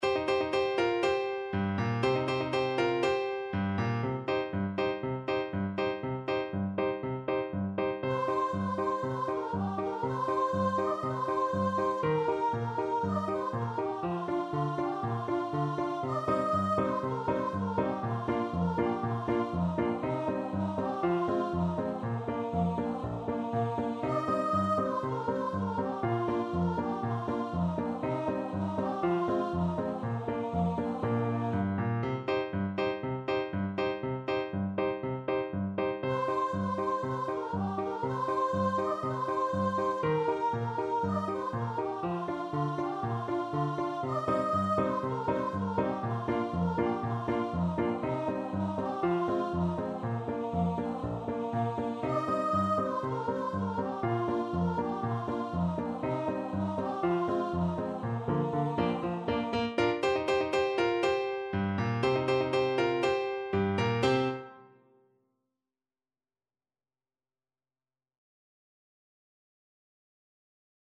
Traditional Music of unknown author.
~ = 120 Allegro (View more music marked Allegro)
Bb4-Eb6